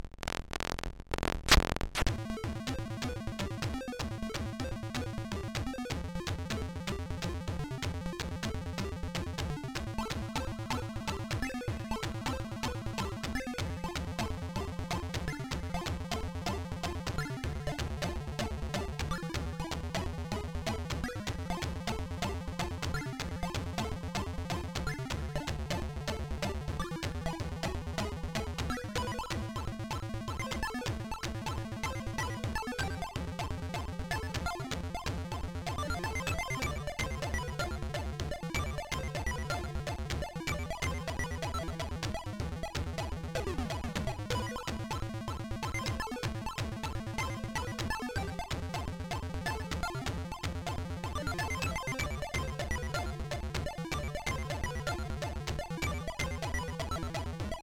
GENRE   : Shoot’Em Up
CONFIG  : Amstrad Plus et GX4000